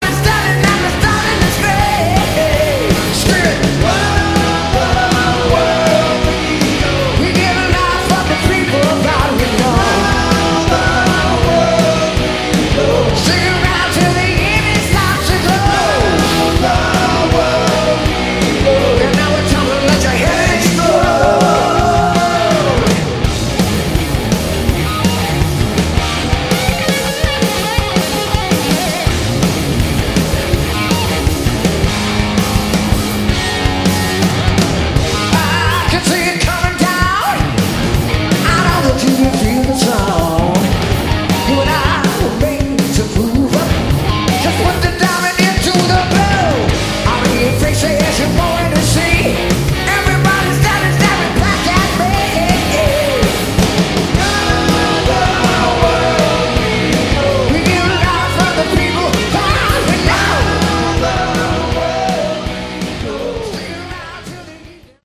Category: Melodic Hard Rock
vocals, guitar
bass, vocals
drums, vocals